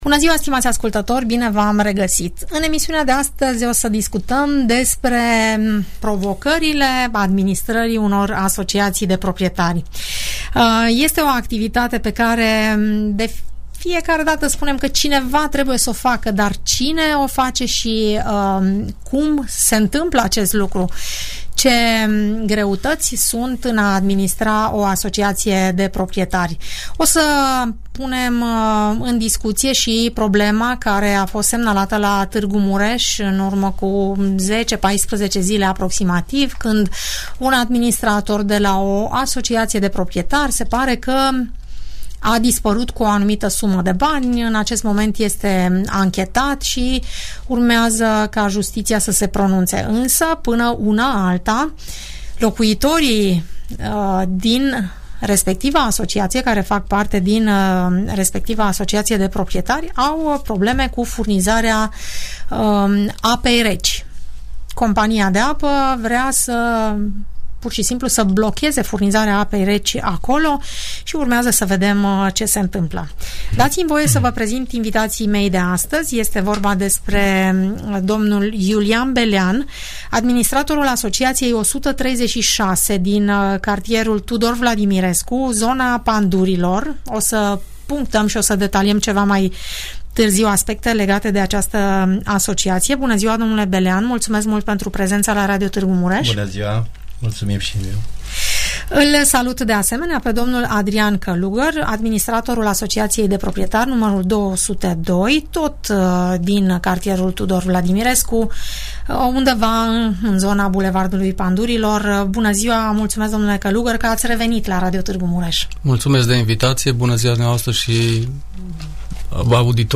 doi administratori invitați în emisiunea "Părerea ta" moderată la Radio Tg Mureș